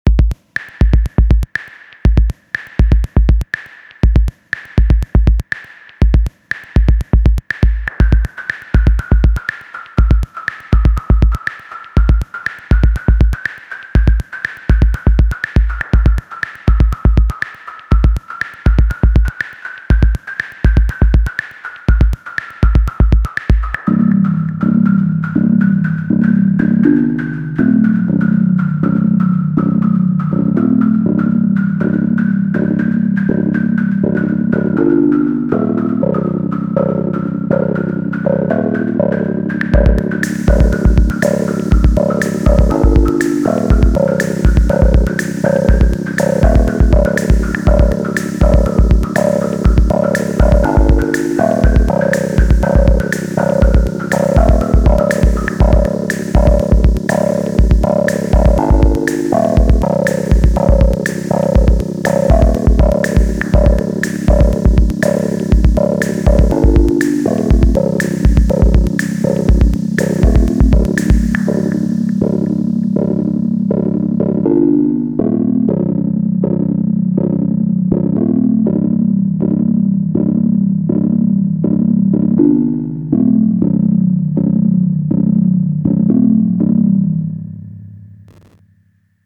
tr 9: UT-Impulse (kick, click, snare)
tr 10: UT-Noise (open hat)
tr 11: UT-Noise (up&down thingy)
tr 12: CB-metallic (bassline)